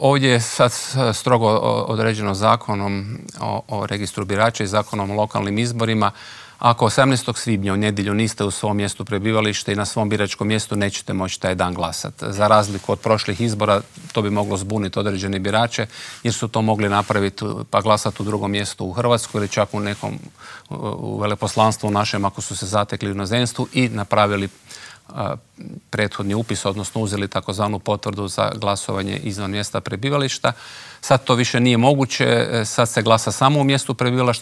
O nadolazećim izborima, pravilima glasovanja, ali i o izbornoj šutnji razgovarali smo u Intervjuu tjedna Media servisa s članom Državnog izbornog povjerenstva Slavenom Hojskim.